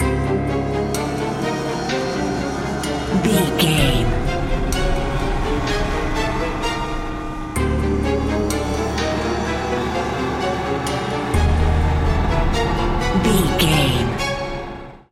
Atonal
drone
medium tempo
violin
drum machine